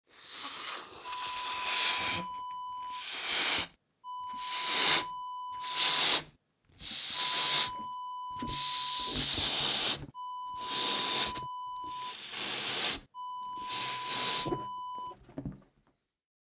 Balloon Blow Up Wav Sound Effect #1
Description: The sound of a balloon being blown up
Properties: 48.000 kHz 24-bit Stereo
A beep sound is embedded in the audio preview file but it is not present in the high resolution downloadable wav file.
Keywords: balloon, blow, blown, blowing, up, party, air, inflate, inflating, fill, filling
balloon-blow-up-preview-1.mp3